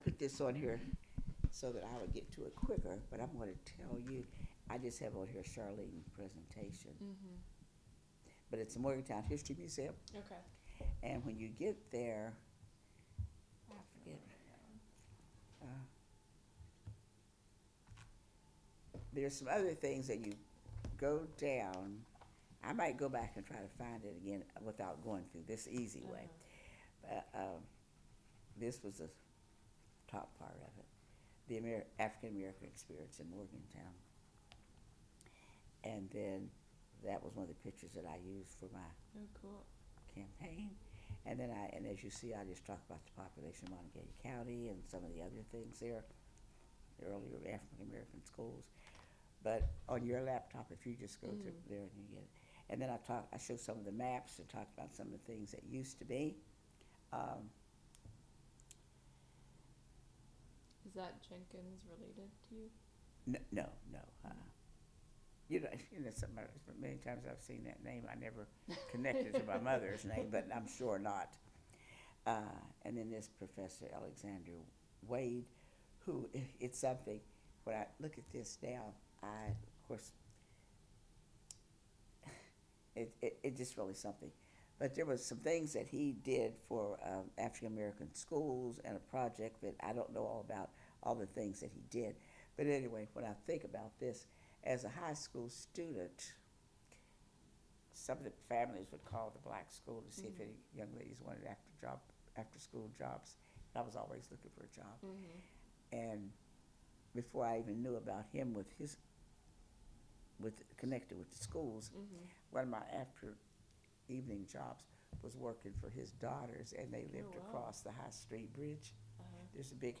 Oral history of Charlene Marshall, 2 of 3
This interview is part of a collection of interviews conducted with Scotts Run natives/residents and/or members of the Scotts Run Museum.
Morgantown (W. Va.) and Scott's Run (W. Va.)